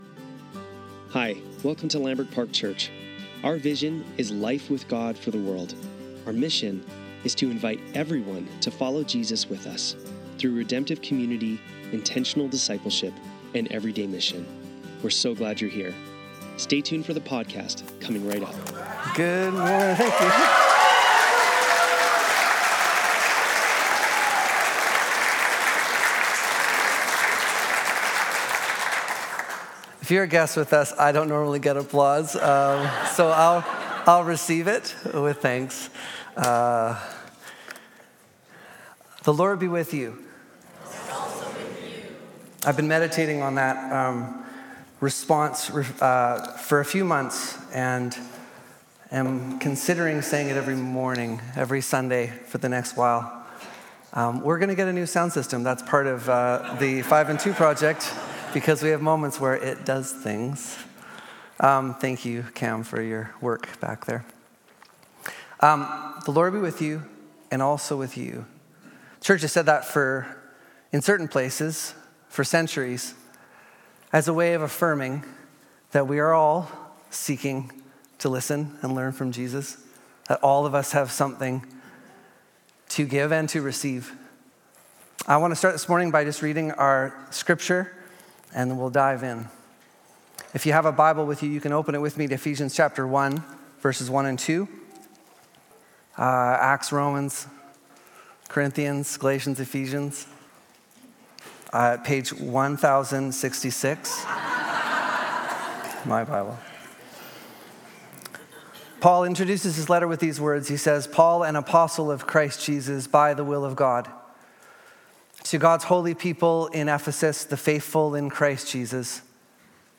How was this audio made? Sunday Service - October 6, 2024